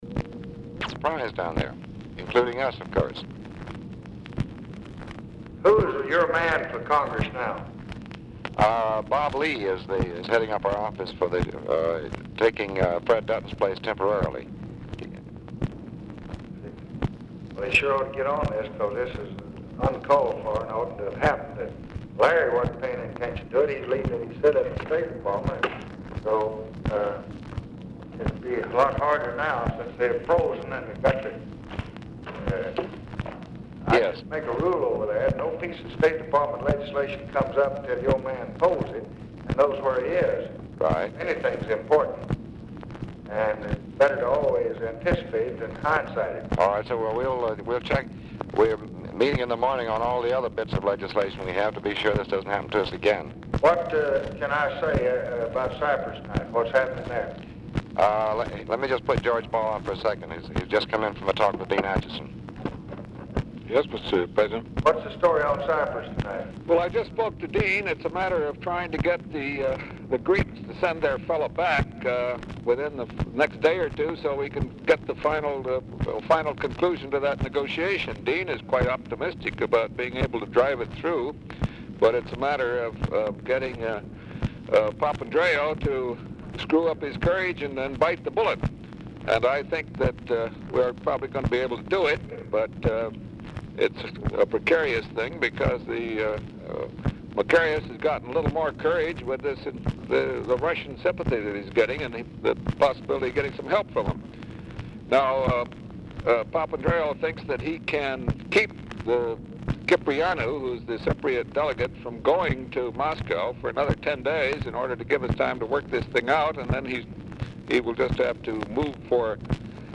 Telephone conversation # 5026, sound recording, LBJ and DEAN RUSK, 8/18/1964, 6:35PM | Discover LBJ
RECORDING STARTS AFTER CONVERSATION HAS BEGUN
Format Dictation belt
Location Of Speaker 1 Oval Office or unknown location